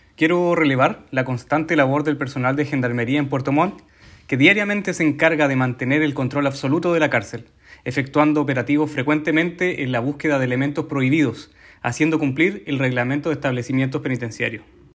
El seremi de Justicia y Derechos Humanos, Cristóbal Fuenzalida Palma, destacó la labor del personal de Gendarmería en Puerto Montt, resaltando su trabajo diario para mantener el control del recinto penitenciario, realizar operativos de búsqueda de elementos prohibidos y garantizar el cumplimiento del reglamento carcelario.